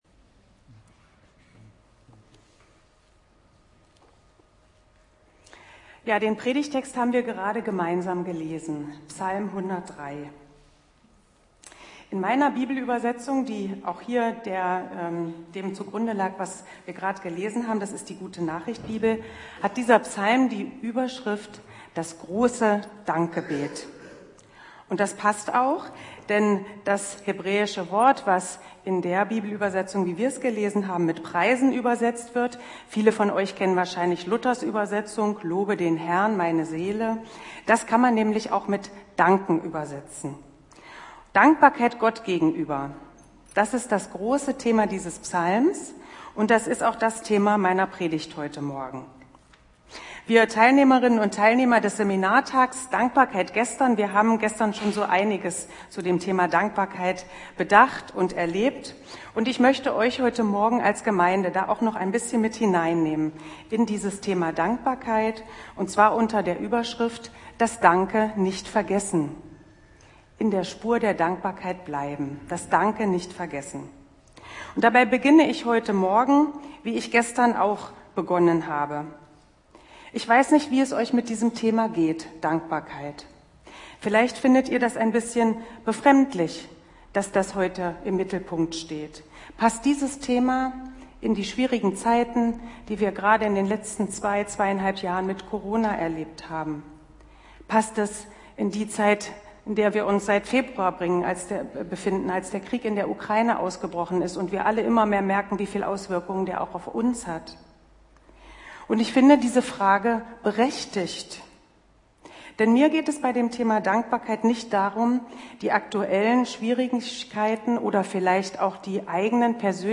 Juni 2022 Predigt Altes Testament , Psalmen Mit dem Laden des Videos akzeptieren Sie die Datenschutzerklärung von YouTube.